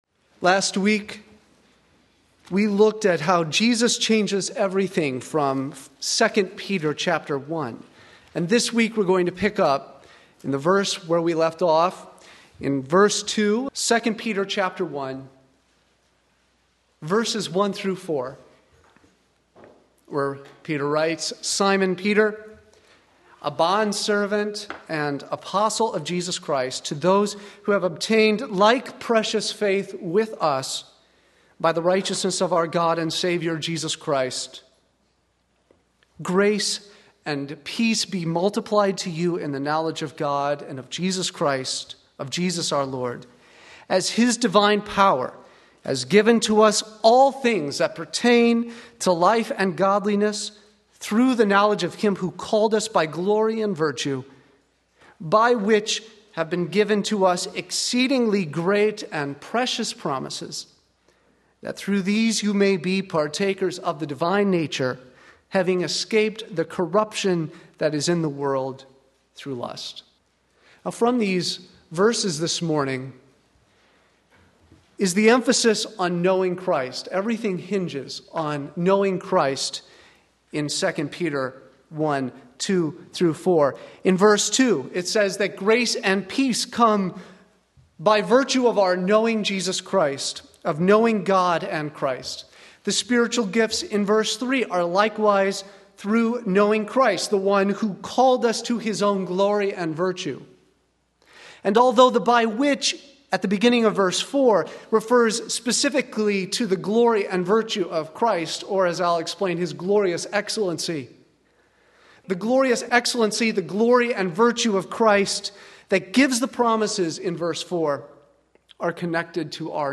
Sermon Link
Knowing Christ 2 Peter 1:2-4 Sunday Morning Service